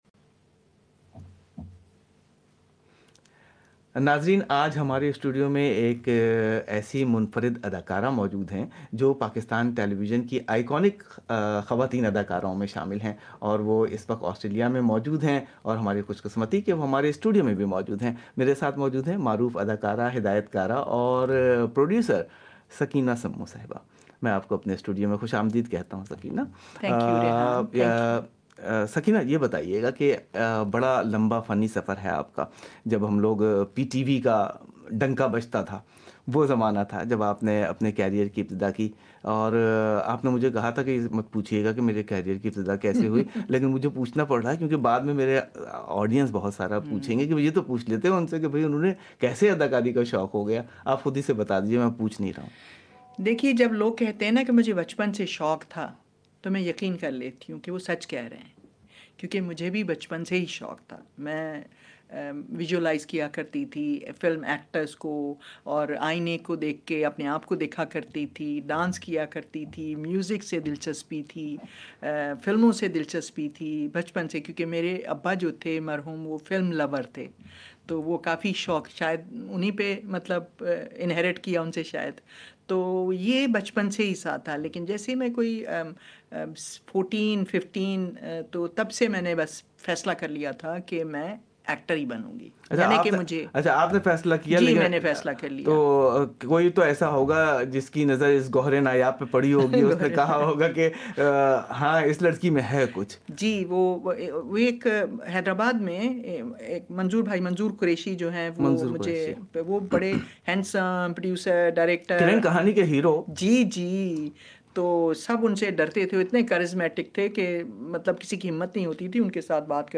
Find out the details in this podcast featuring conversations with Uzmi Gillani and Sakina Samo.